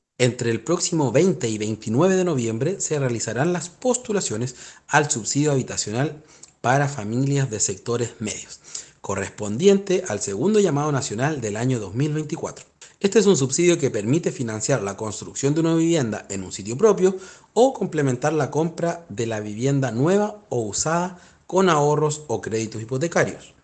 Asimismo, para entregar una mejor atención a las familias que postulen en este llamado y optimizar el uso de los sistemas frente a la alta demanda, la atención se efectuará en fechas escalonadas de acuerdo con las postulaciones automática, en línea y vía formularia de atención ciudadana, como explicó Fabián Nail, Seremi de Vivienda y Urbanismo.